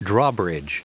Prononciation du mot drawbridge en anglais (fichier audio)
Prononciation du mot : drawbridge